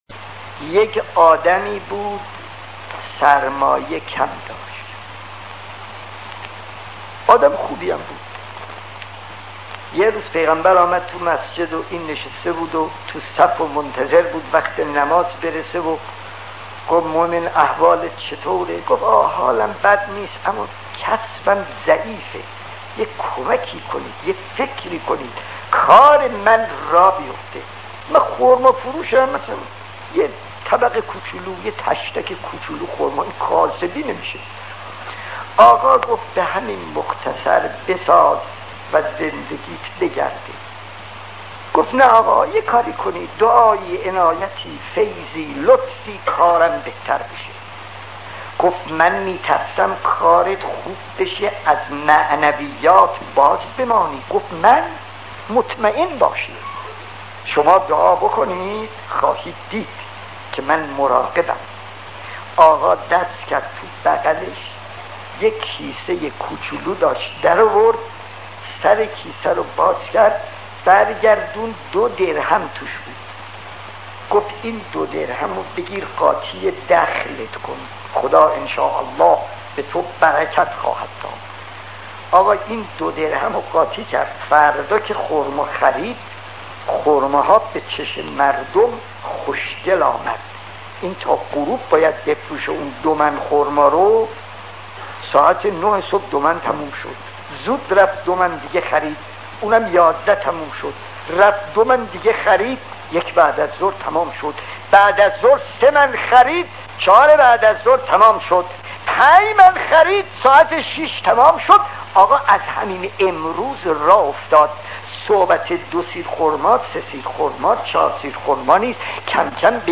داستان 49 : مرد خرمافروش زیاده خواه خطیب: استاد فلسفی مدت زمان: 00:05:25